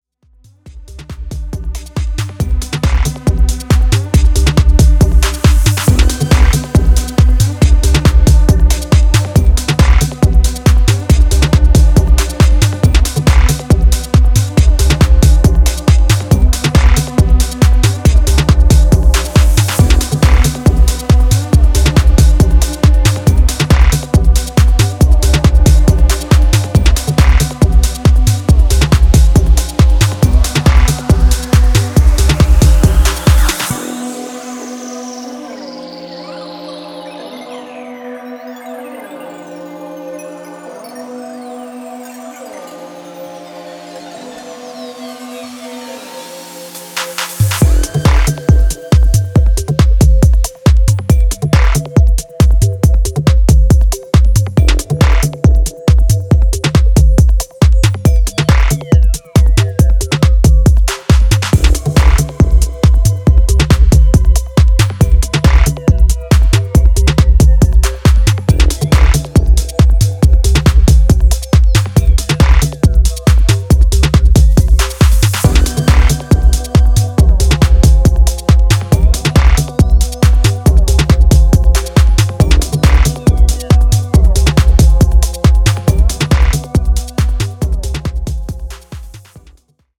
House Bass